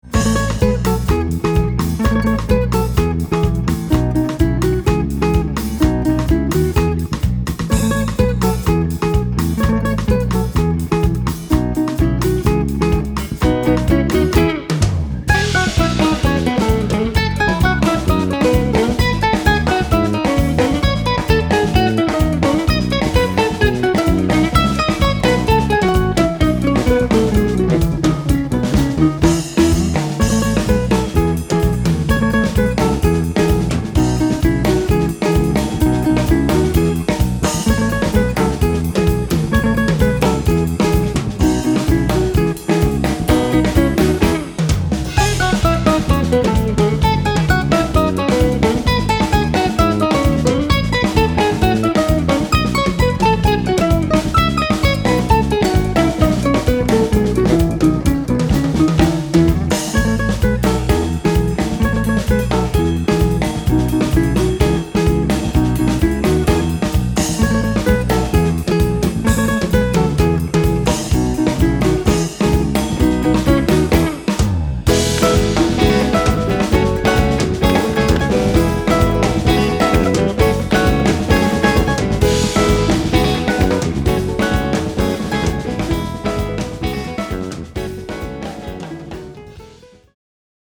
Elektrische und akustische Gitarre
E-Bass
Schlagzeug